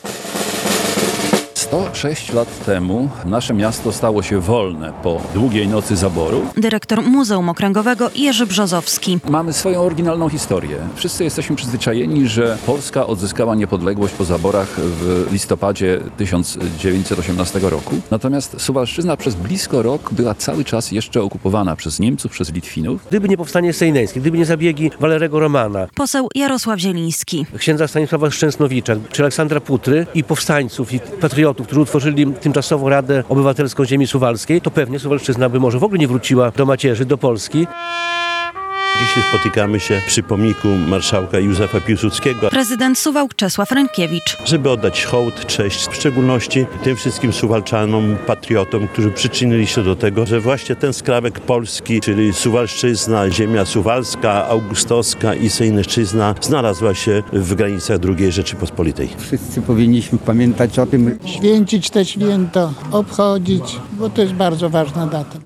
relacja
Główne uroczystości odbyły się pod pomnikiem marszałka Józefa Piłsudskiego.